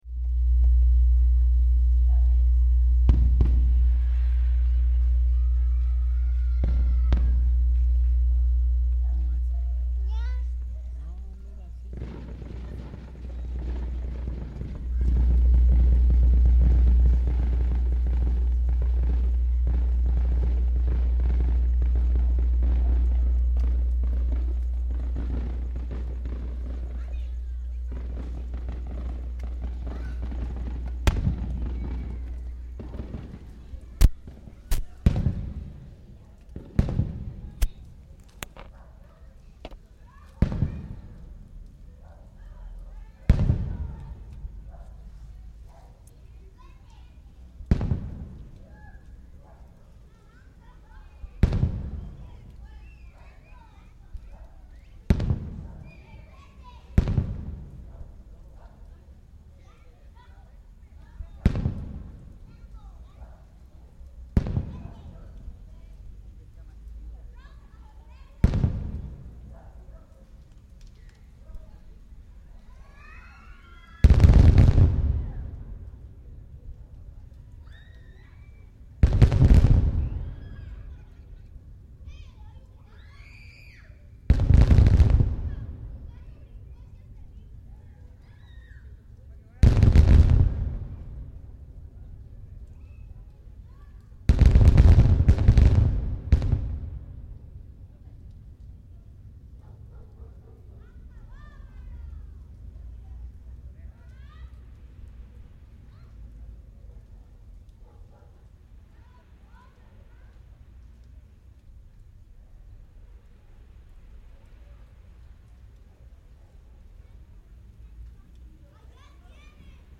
A pocos minutos de haber terminado el partido de futbol entre Mexico y Canadá esto es lo que escuchamos y vimos a unos cuantos kilómetros del estadio Víctor Manuel Reyna.
Lugar: Tuxtla Gutierrez, Chiapas; Mexico.
Equipo: Minidisc NetMD MD-N707, micrófono de construcción casera (más info)